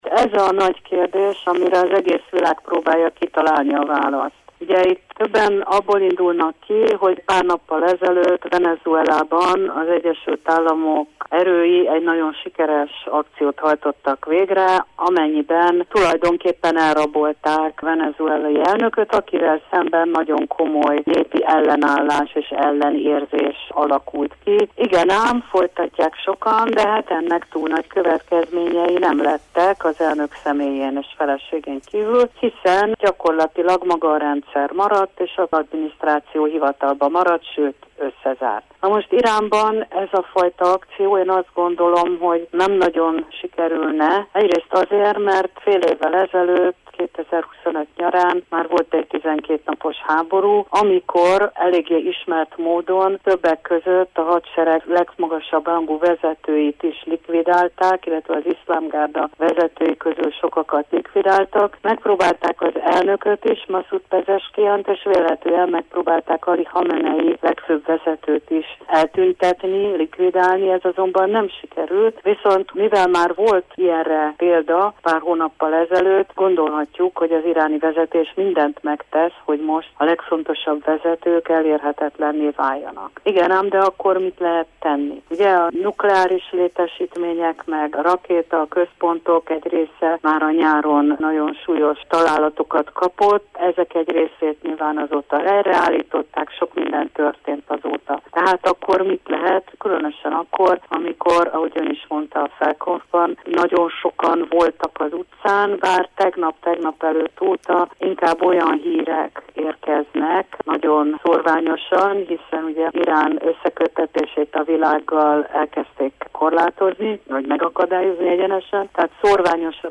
Mi várhat az iráni vezetésre és mit jelentene az amerikai segítség, amely Donald Trump elmondása szerint már úton van? Ezen kérdések mentén beszélget